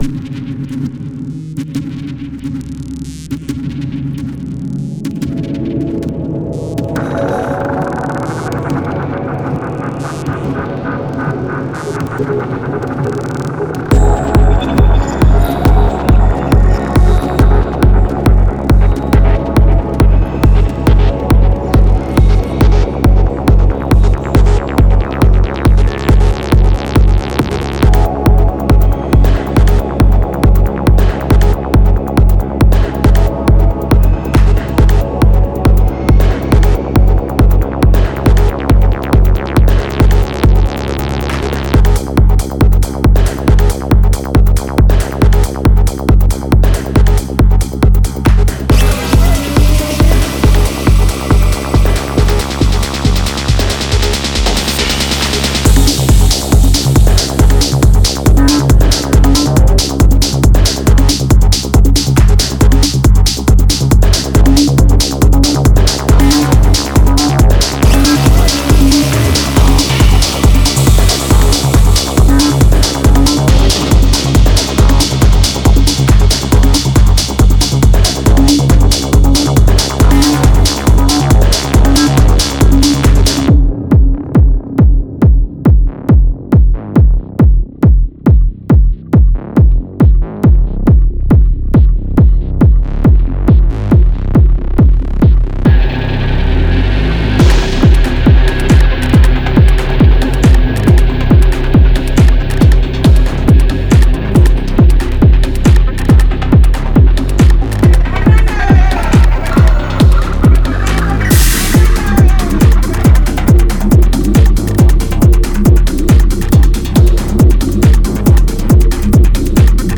さらに、細かく作り込まれたパーカッションループでグルーヴをキープし、多彩なFXサウンドでグリットと雰囲気を追加。
デモサウンドはコチラ↓
Genre:Techno